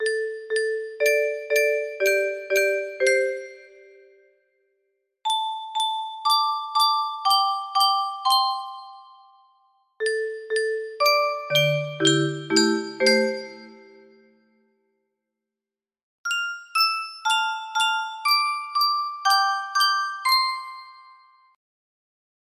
Lost Memories music box melody
Full range 60